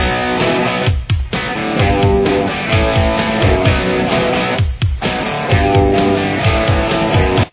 Verse
This riff has 2 guitar parts.